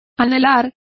Complete with pronunciation of the translation of yearned.